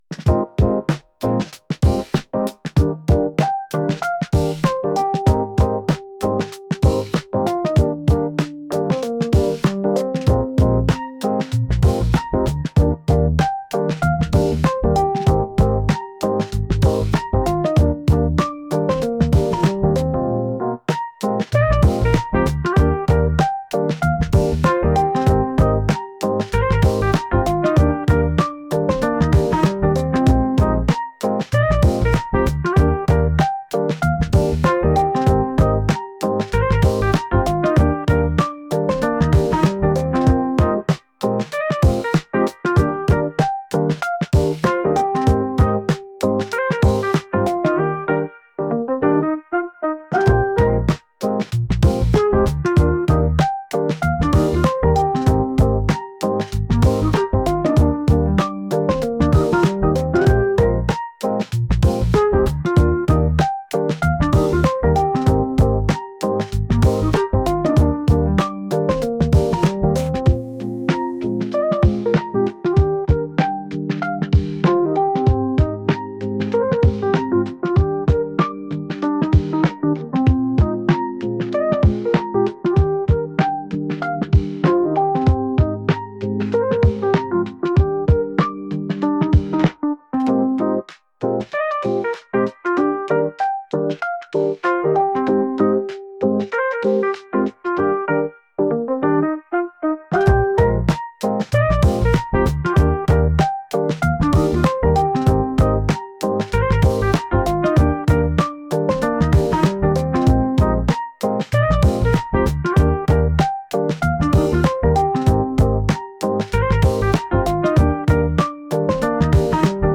宿題に一夜漬けで取り組んでいる時にきくような曲です。